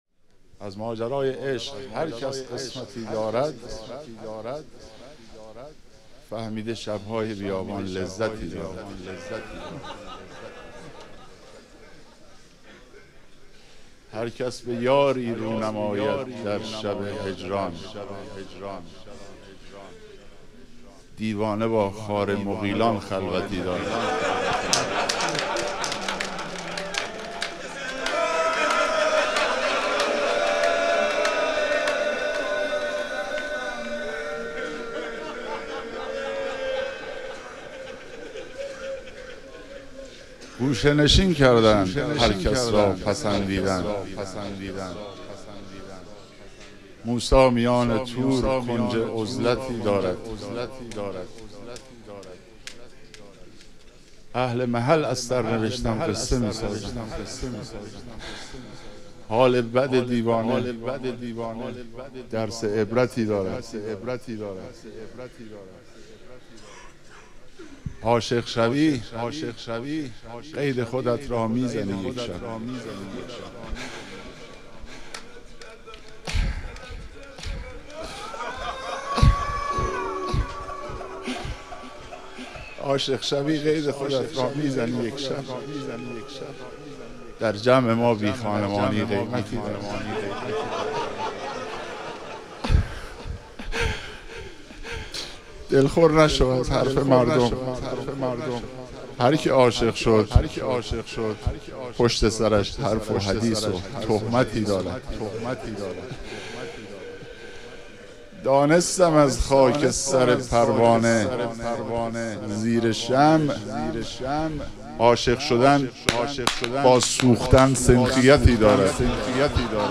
مدح و رجز